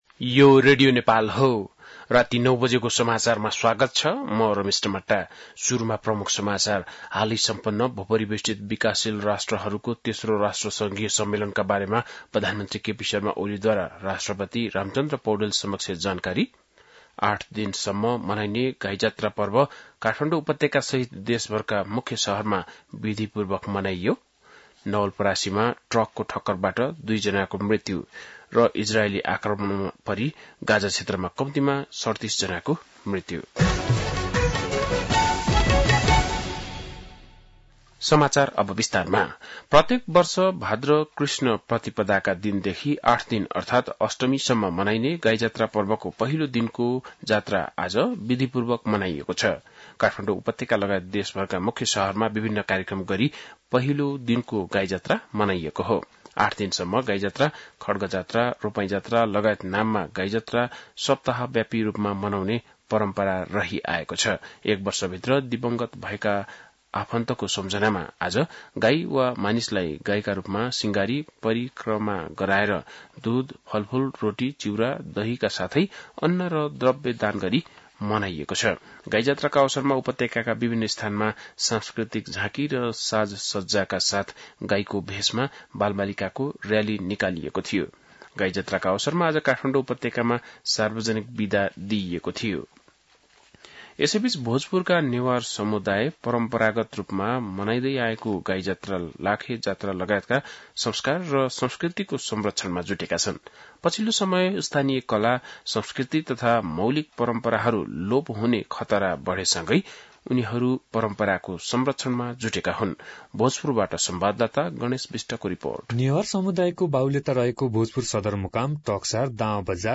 बेलुकी ९ बजेको नेपाली समाचार : २५ साउन , २०८२
9-pm-nepali-news-4-25.mp3